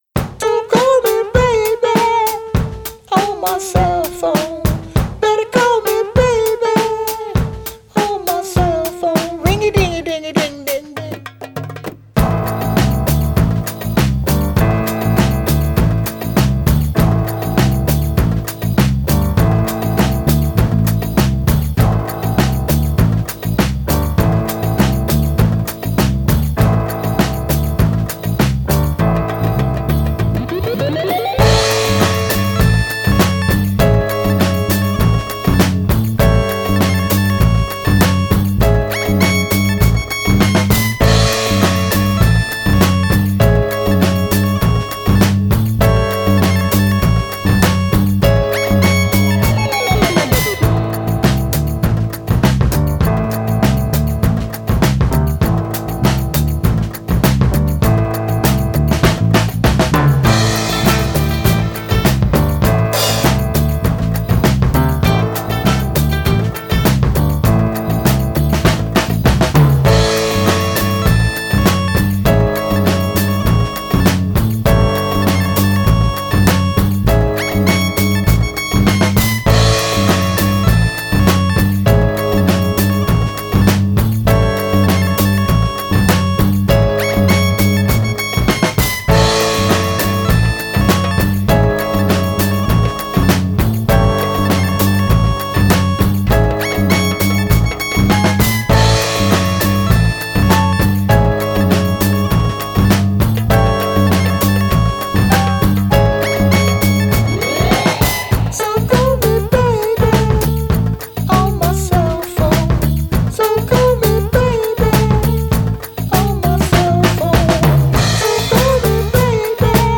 Acid Jazz, Psychedelic